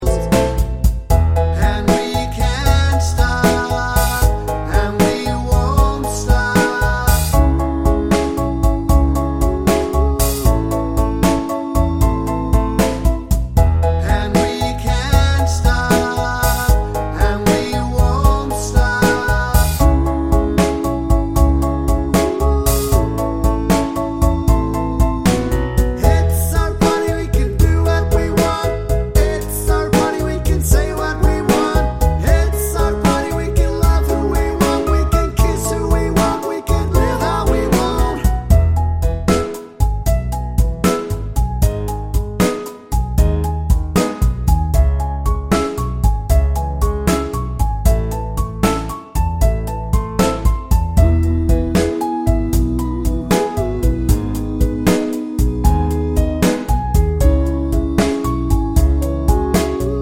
no Backing Vocals Jazz / Swing 4:00 Buy £1.50